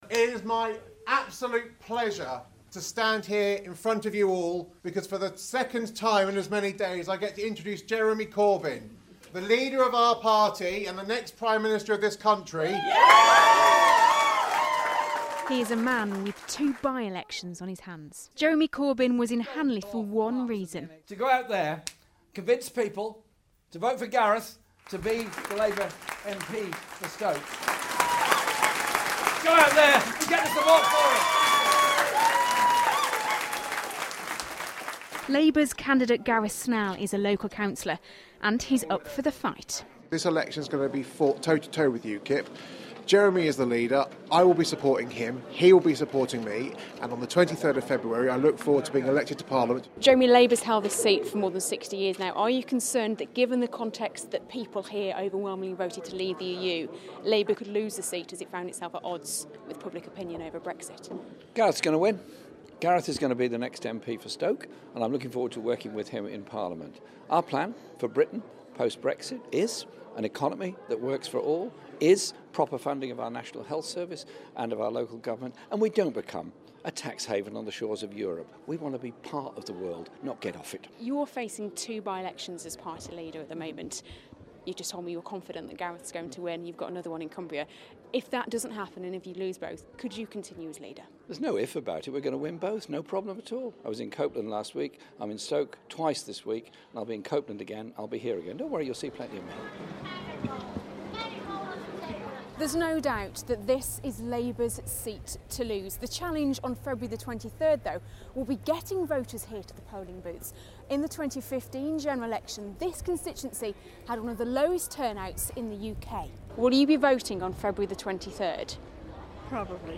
He arrived at a rally in Hanley, introduced to the stage by the candidate for February's by-election, Councillor Gareth Snell.